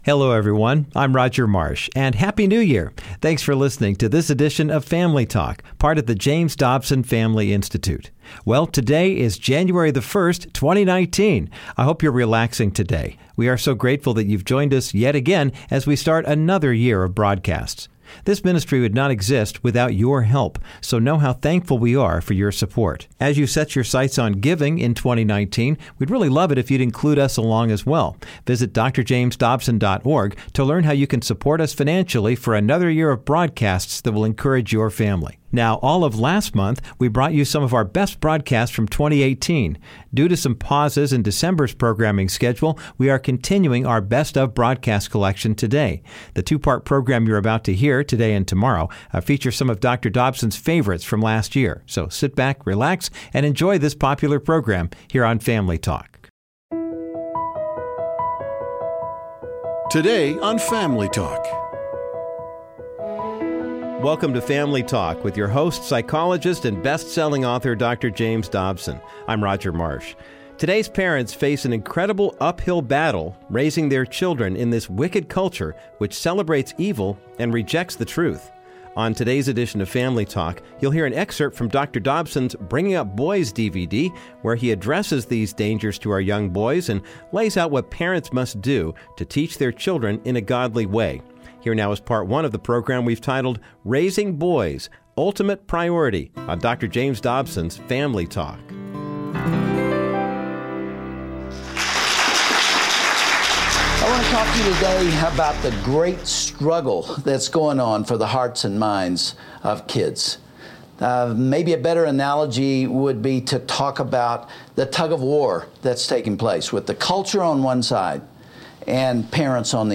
Dr. Dobson addressed this topic many years ago, through a speech he gave on his popular Bringing Up Boys DVD, today on Family Talk. He discussed the threats young men face, and how parents can protect their children from the negative influences of culture.